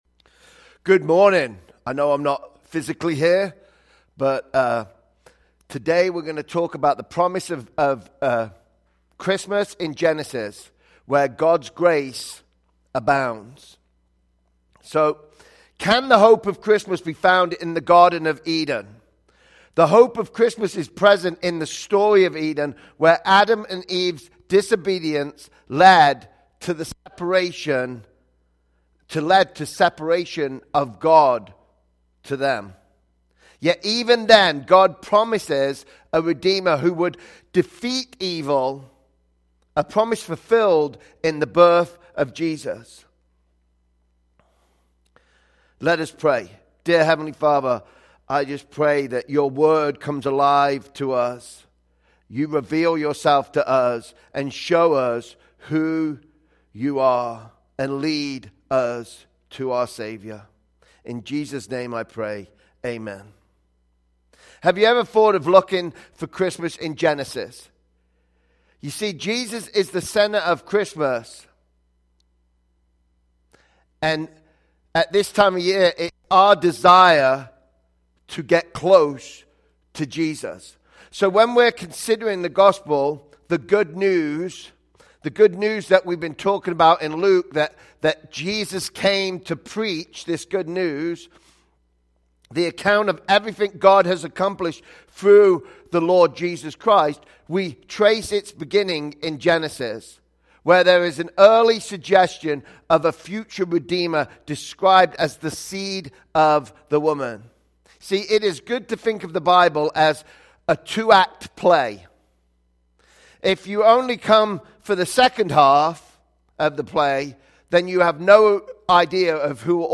Sermons by Life815